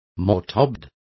Complete with pronunciation of the translation of mortarboard.